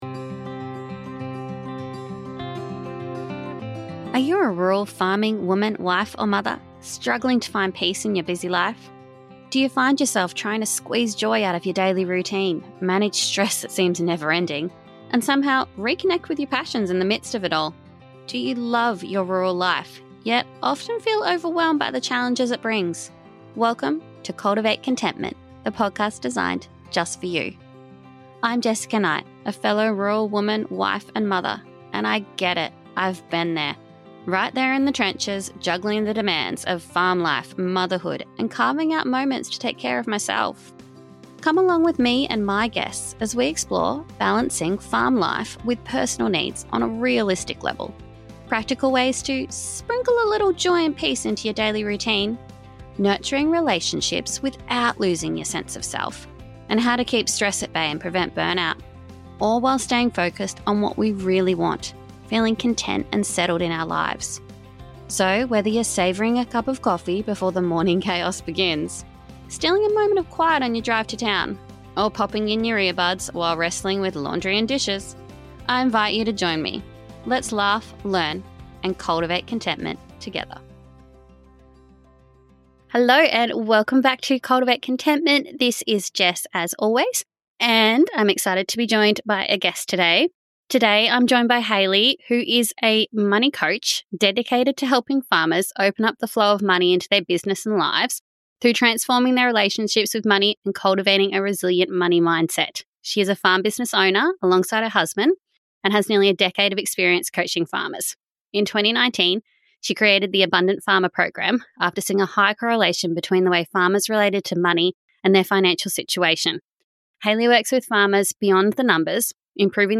If you're looking to cultivate a positive money mindset and embrace a more abundant approach to farming, this episode is packed with practical tips and heartfelt conversation that I hope inspires you as much as it did me.